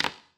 surface_wood_tray1.mp3